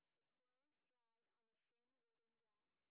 sp15_street_snr10.wav